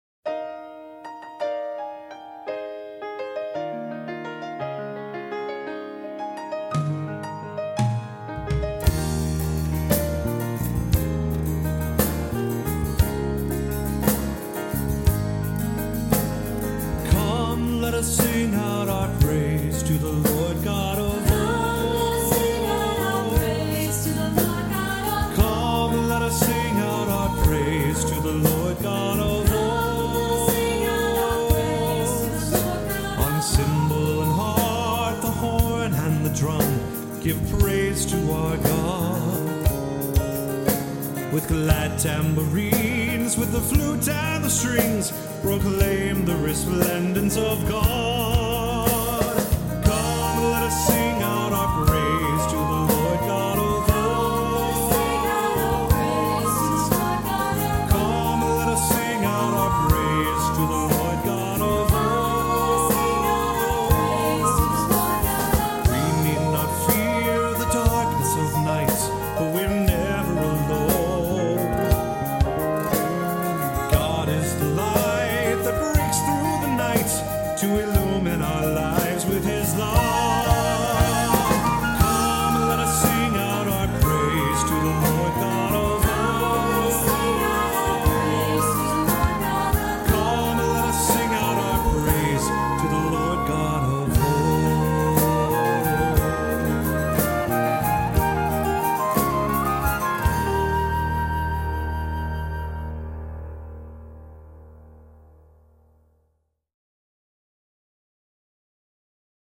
Voicing: SAB and Piano